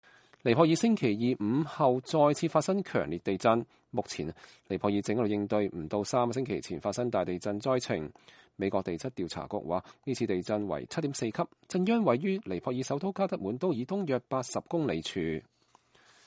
加德滿都民眾慌忙走避